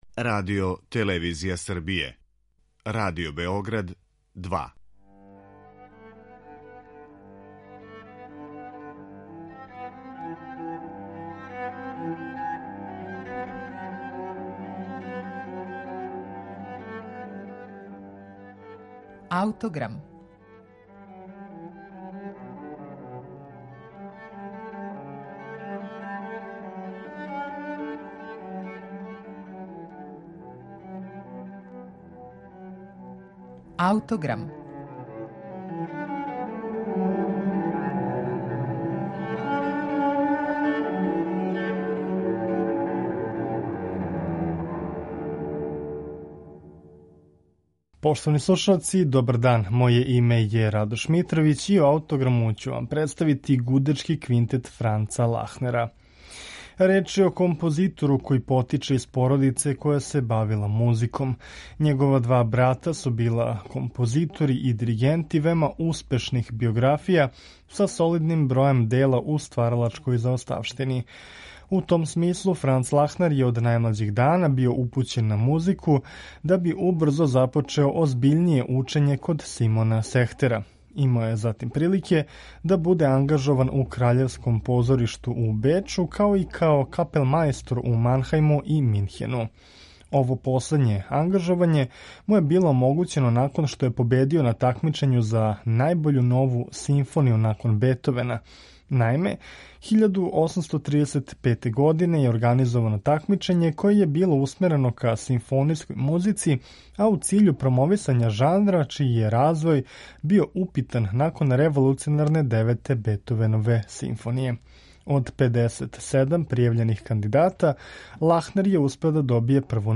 Иако је посебно био заинтересован за оркестарски звук, у његовом стваралаштву се проналазе и камерна остварења. Ми ћемо тако чути Гудачки квинтет у це-молу, написан 1866. године. Слушаћемо извођење ансамбл „Musica Varia".